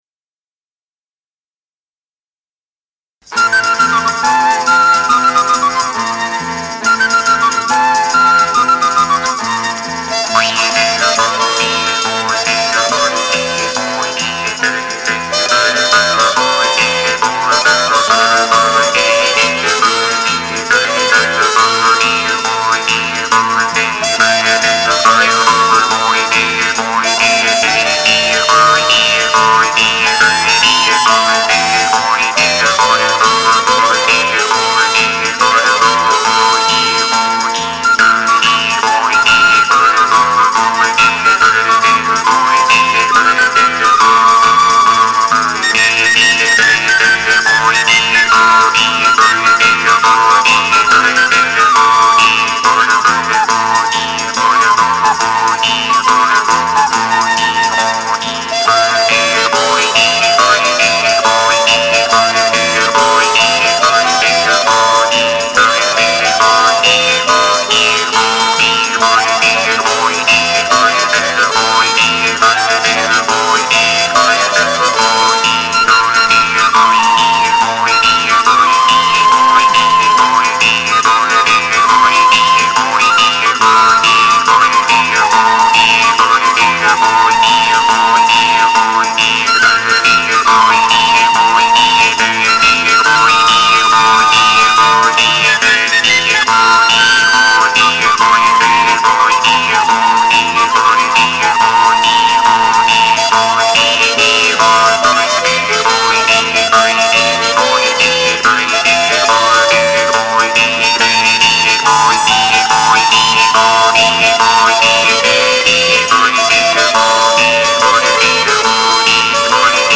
(SCACCIAPENSIERI - JEW' S HARP)